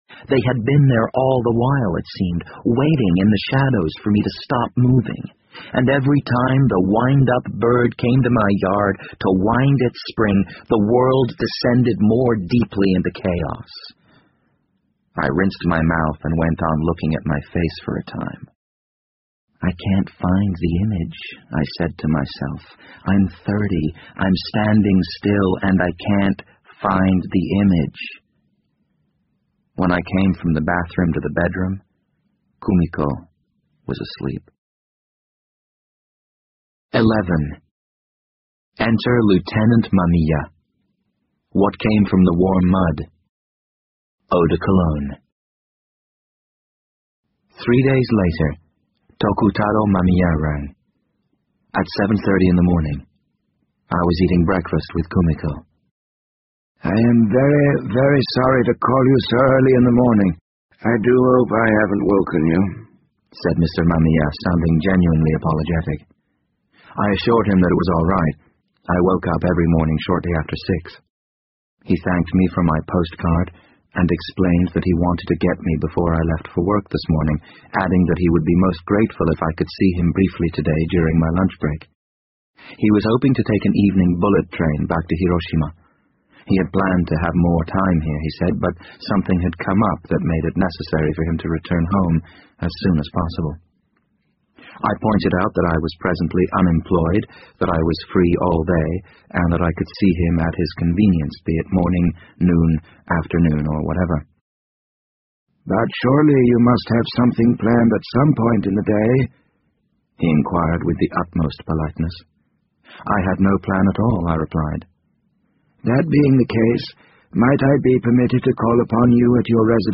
BBC英文广播剧在线听 The Wind Up Bird 63 听力文件下载—在线英语听力室